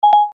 короткие
электронные